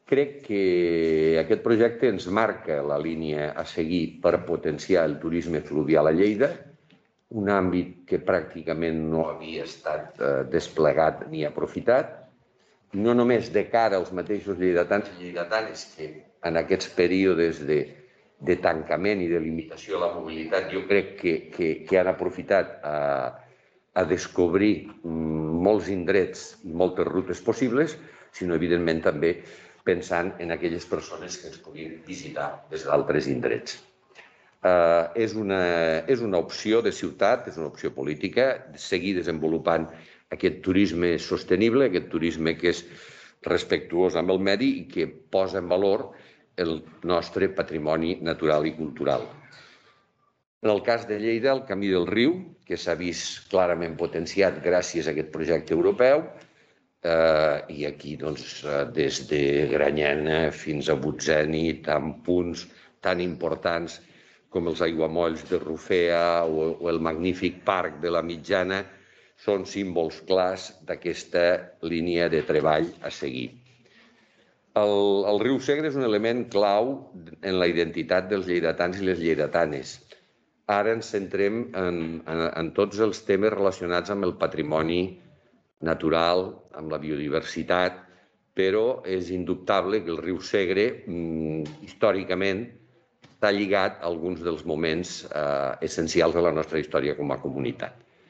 Fitxers relacionats Tall de veu de l'alcalde, Miquel Pueyo, sobre el projecte europeu TTFS a Lleida (982.6 KB) Tall de veu del tinent d'alcalde Sergi Talamonte sobre el projecte europeu TTFS a Lleida (1.0 MB)
tall-de-veu-de-lalcalde-miquel-pueyo-sobre-el-projecte-europeu-ttfs-a-lleida